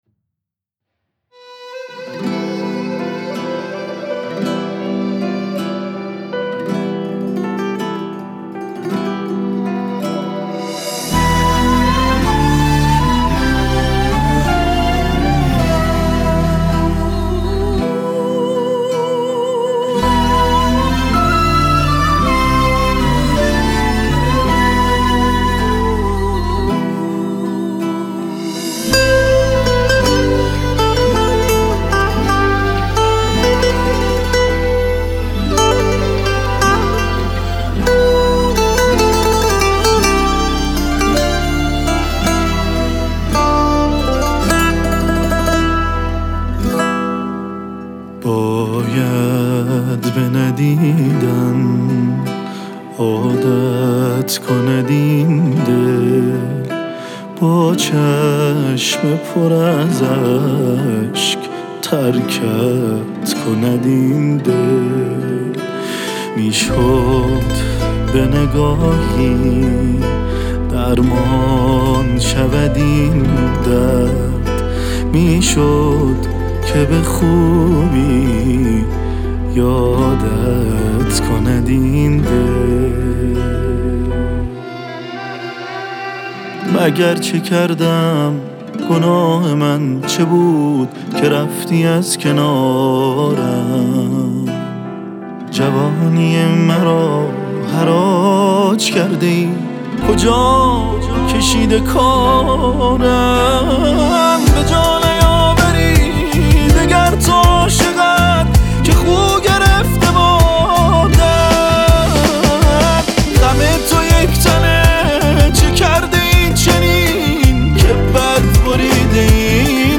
سنتی، محلی و تلفیقی